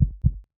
heartbeat.ogg